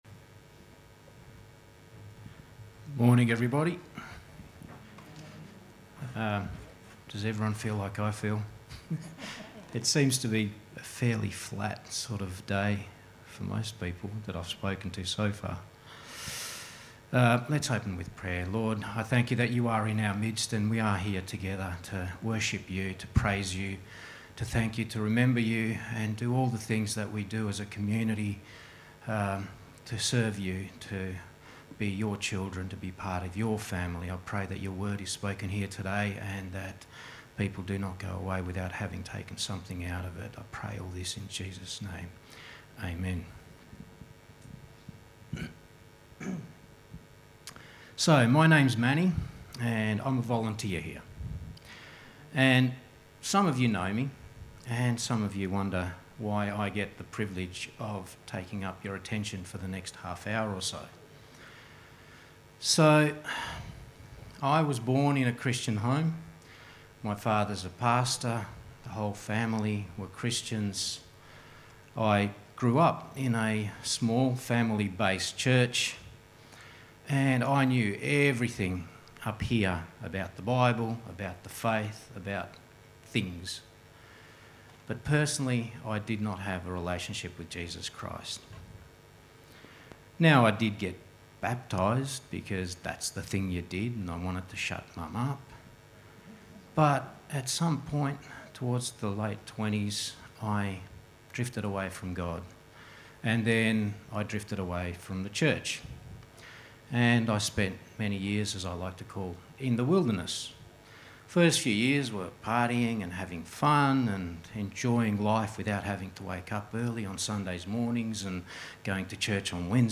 A message from the series "Walking Together."